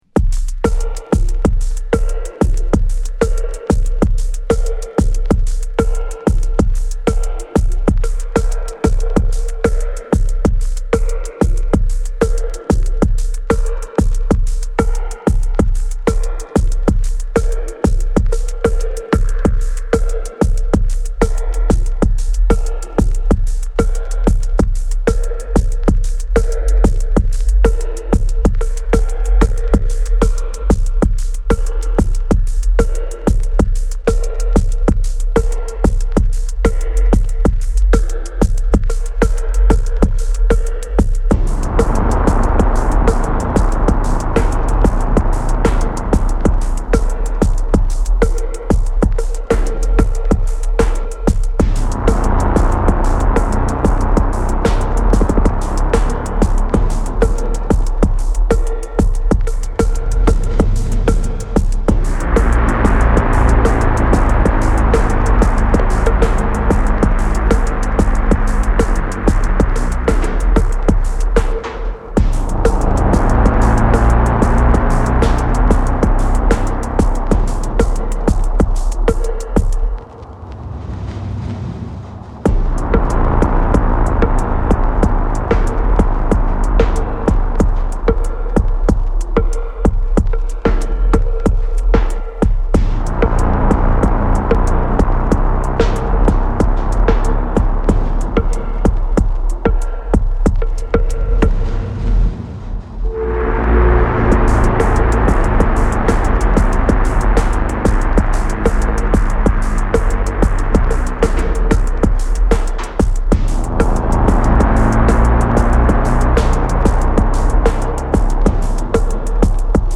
テクノミニマル
シンプルなパーカッションに地底からの咆哮のような内図が押し寄せる